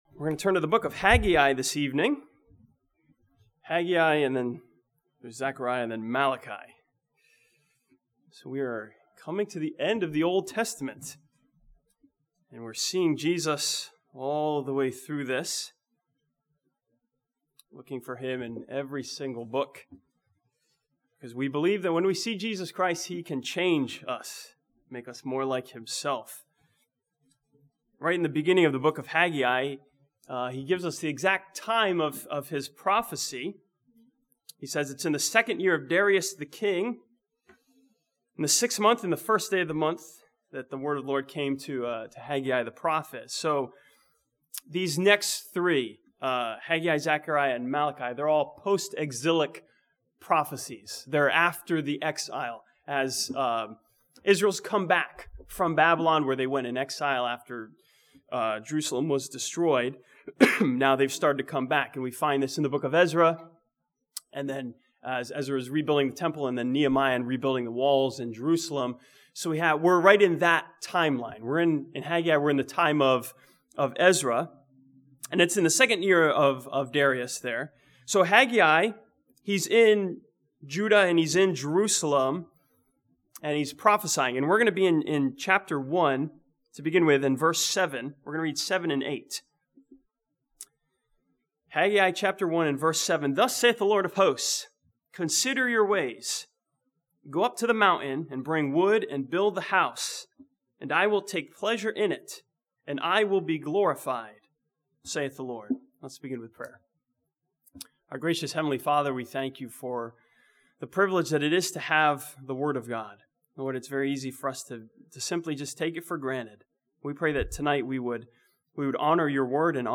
This sermon from the book of Haggai sees Jesus in the rebuilding of the temple as the latter house that would be glorious.